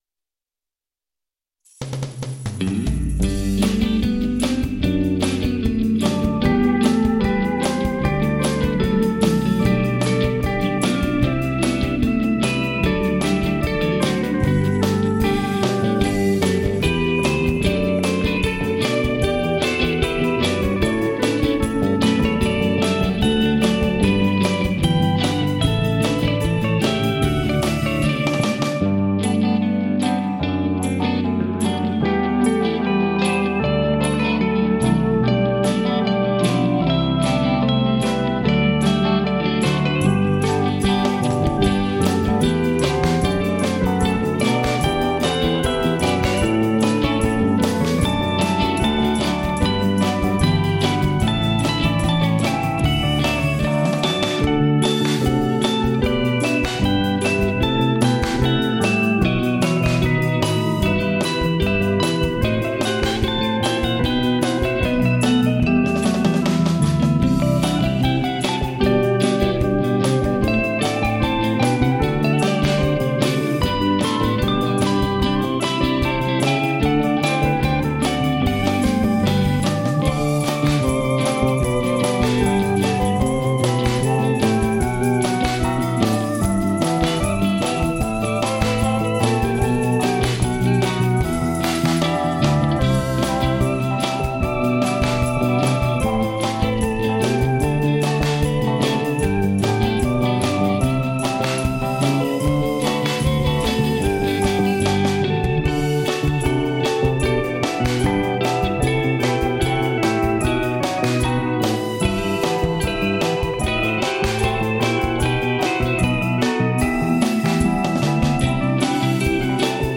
guitars, bass, organ, additional percussion
organ, e-piano, drums, tambourine
• Microphones: Shure SM-57